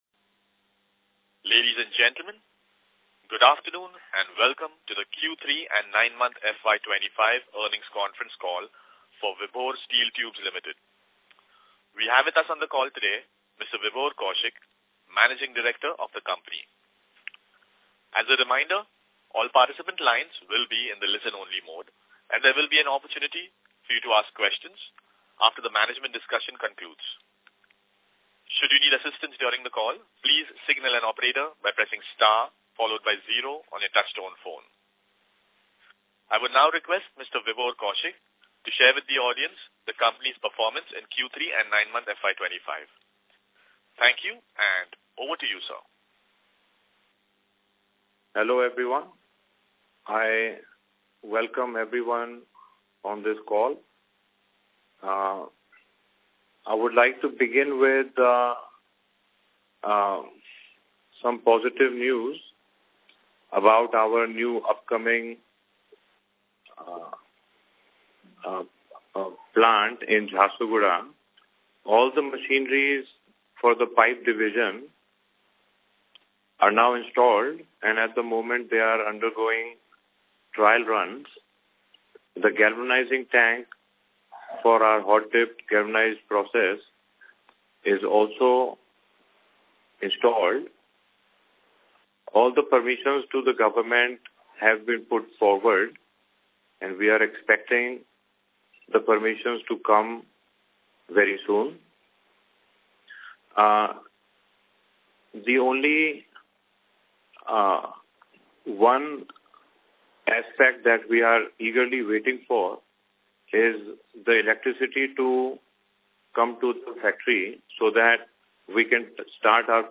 Q2 FY 24-25 - Audio Recording of Earnings Call